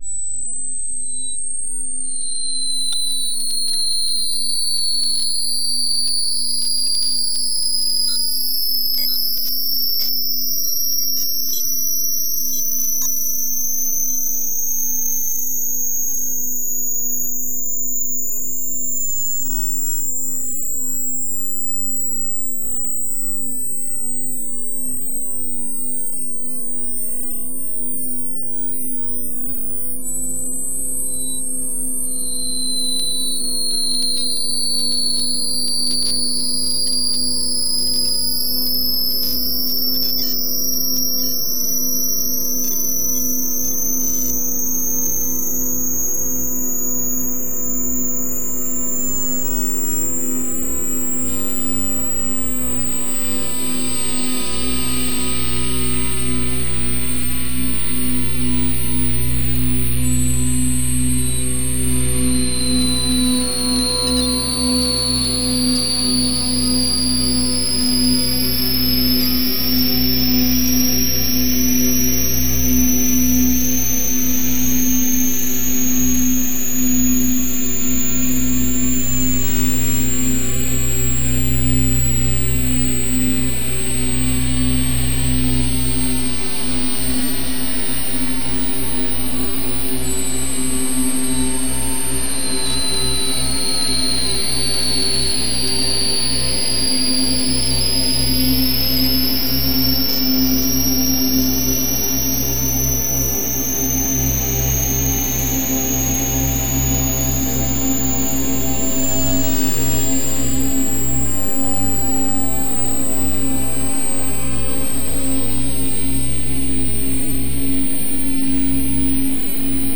本作はギター・オーケストレーション・アルバム。
各々の弦振動は様々な音響処理が施され、
もはや元来がギターであったか否かという事さえ問題にならないような楽曲になっています。
特に超音波帯域でのオーケストレーション、様々に有機的な感じで変速するパンニング、
アンビエントとして生活空間の背景に漂わせてお楽しみ頂くのも良いですが、